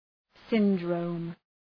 Shkrimi fonetik {‘sındrəʋm}